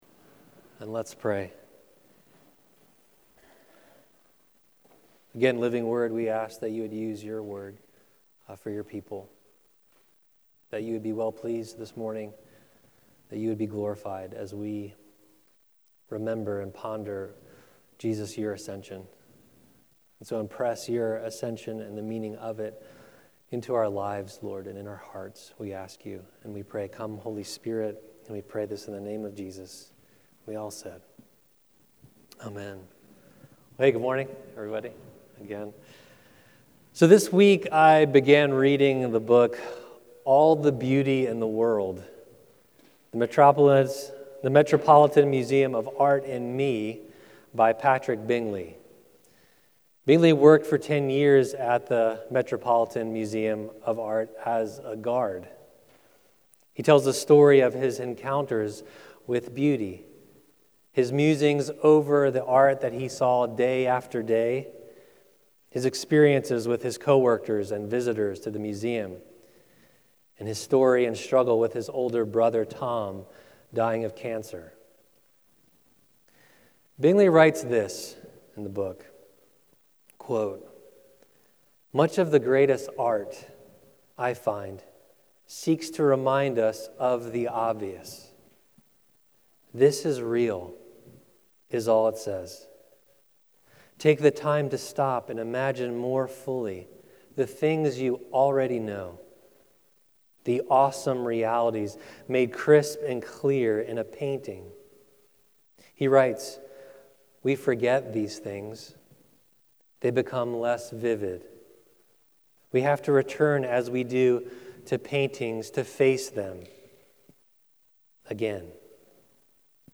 Current Sermon Ascension Sunday